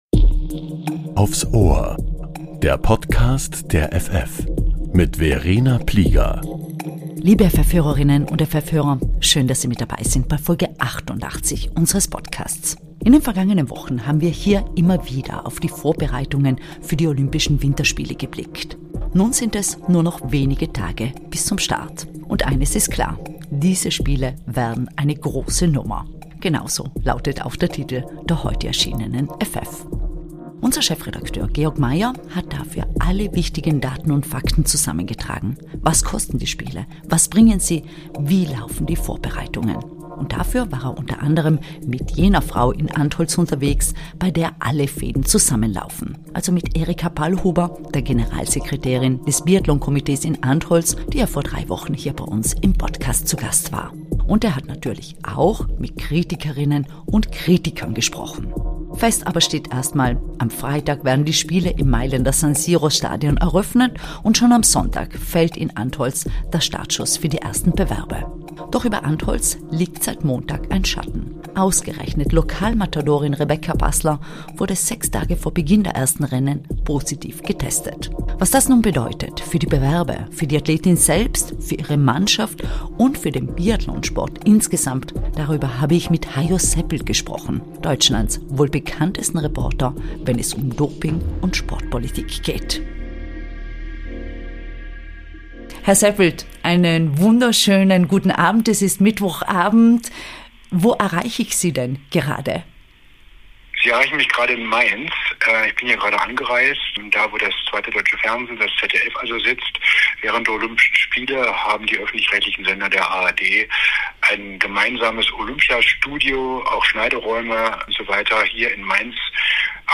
Hajo Seppelt, Doping‑Experte der ARD, spricht über den Fall Passler und Doping im Wintersport